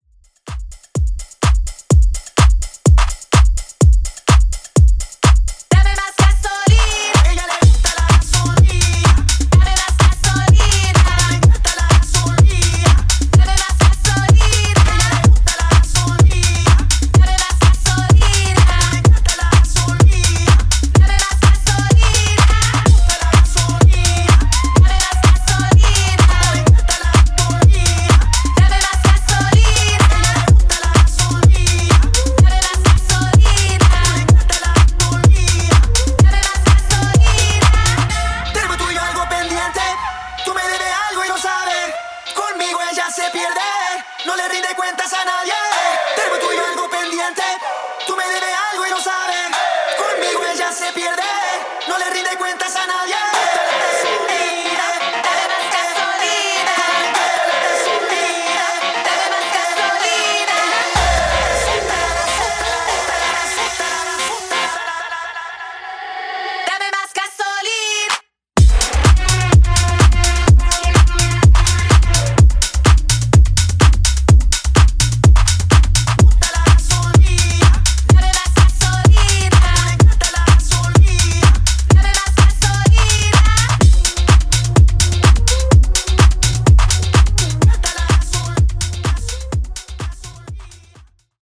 Extended Club House)Date Added